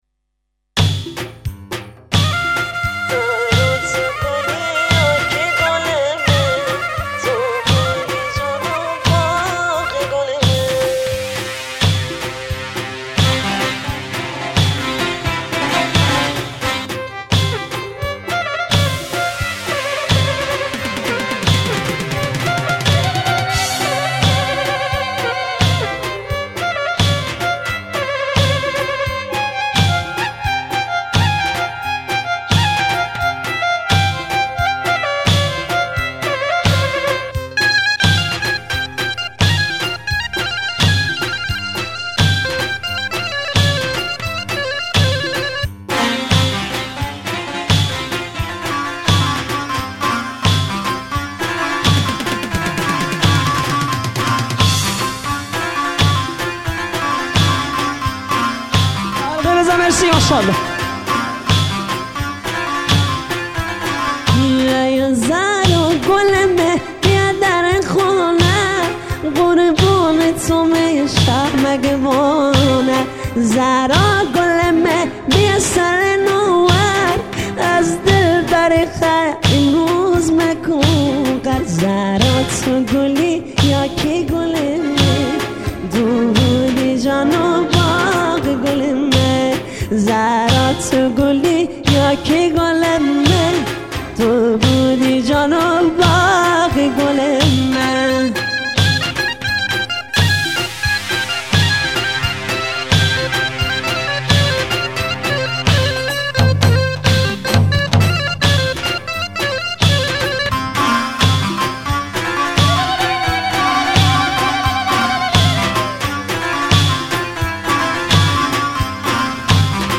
آهنگ